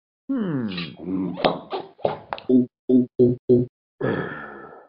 Talking Ben Drinking Sound Effect Free Download
Talking Ben Drinking